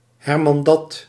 Ääntäminen
US : IPA : [pə.ˈlis]